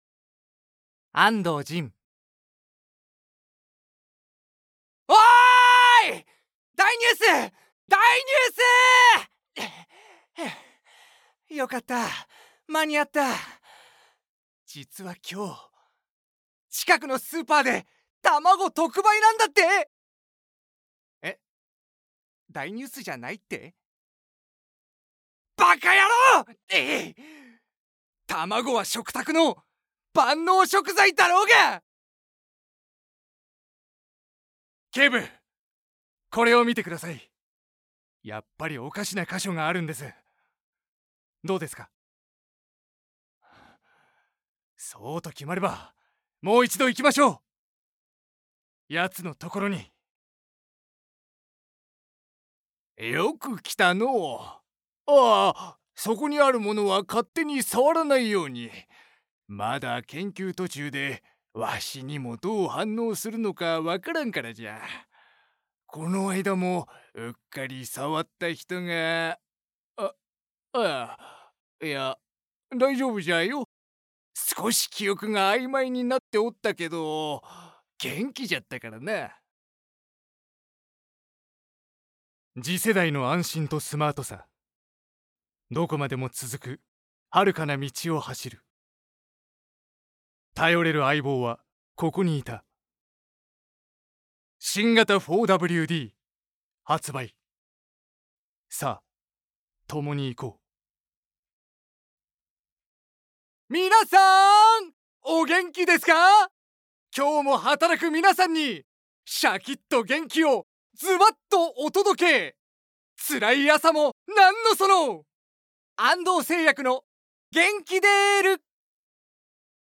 所属声優男性
サンプルボイス